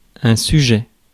Ääntäminen
France: IPA: [sy.ʒɛ]